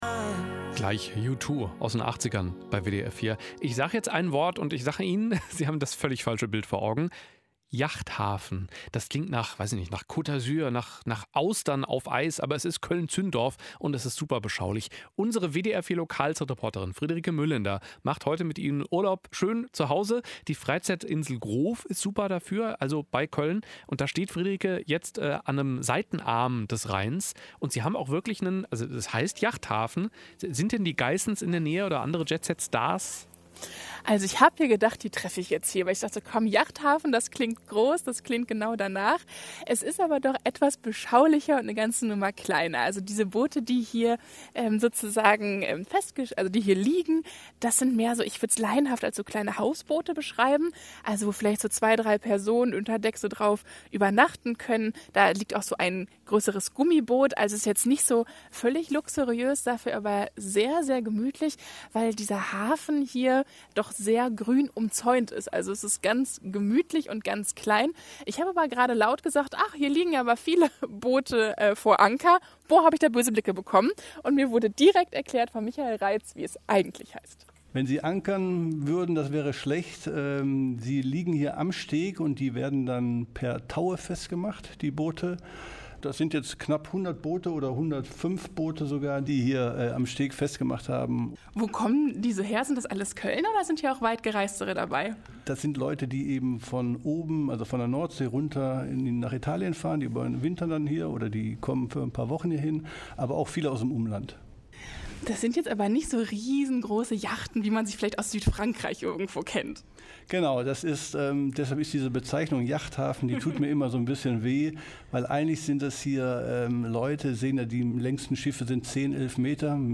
Der WDR-Hörfunk ist den ganzen Sommer unterwegs in der Kölner Region und gibt Ausflugstipps und stellt schöne Orte und Sehenswürdigkeiten vor. Im Rahmen dieser Radiosendung besuchte eine Reporterin das Zündorfer Freizeitgelände mit Hafen, Groov und Zündorfer Altstadt.
wdr4-x-yachthafen.mp3